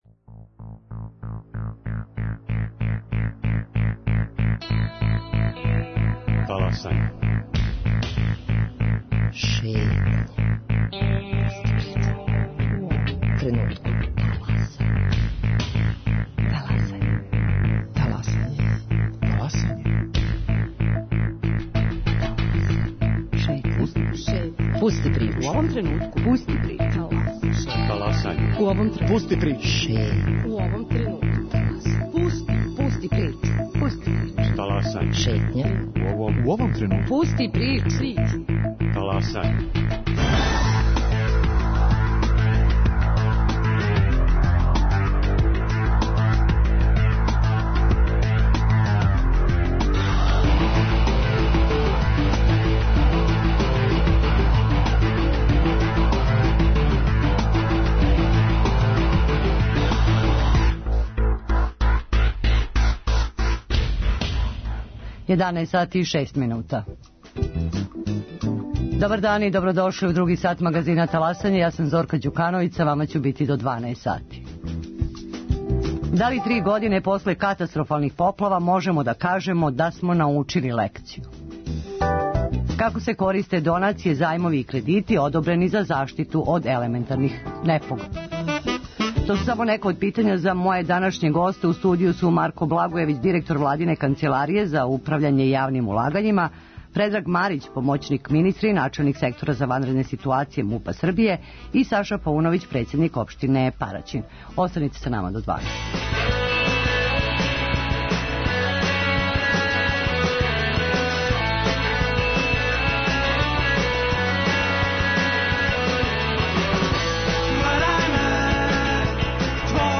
Гости: Марко Благојевић, директор владине Канцеларије за управљање јавним улагањима, Предраг Марић, начелник Сектора за ванредне ситуације МУП-а Србије и Саша Пауновић, председник општине Параћин.